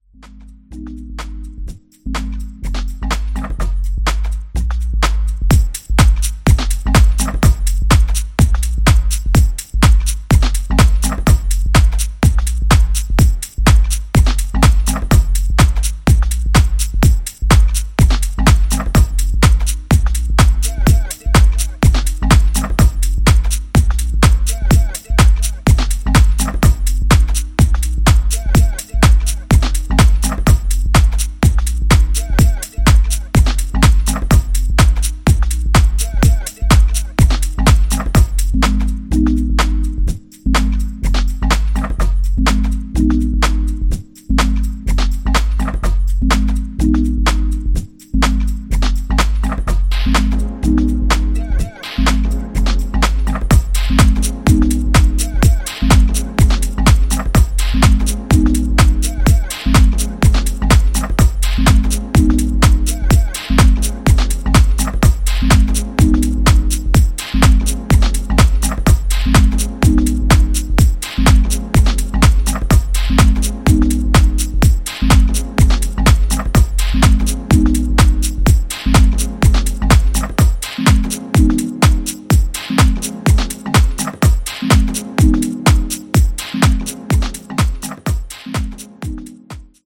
US産らしい厚みあるミニマル/ハウスを融合したナイスなデトロイト・ディープ・ハウスを展開する全4トラック。
ジャンル(スタイル) HOUSE / DEEP HOUSE